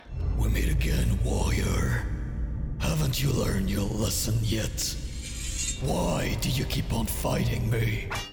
中低音域の声で、暖かさと親しみやすさを兼ね備えています。
太い声(英語)